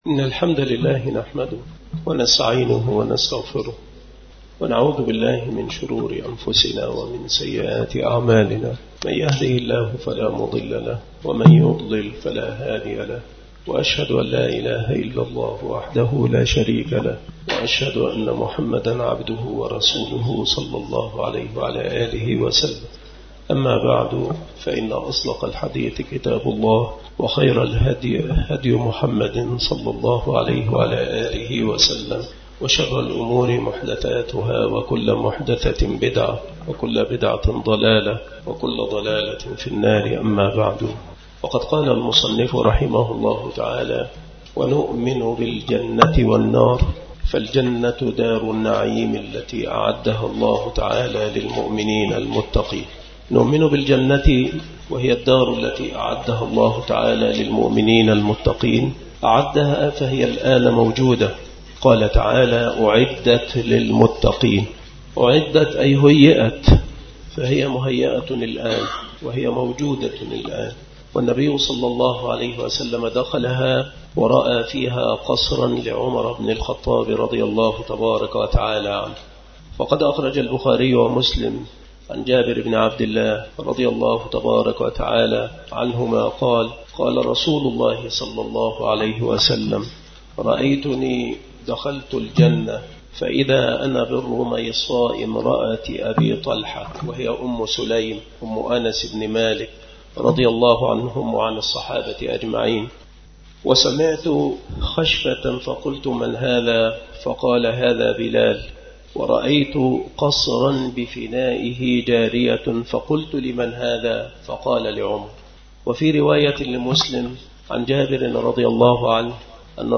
مكان إلقاء هذه المحاضرة بالمسجد الشرقي بسبك الأحد - أشمون - محافظة المنوفية – مصر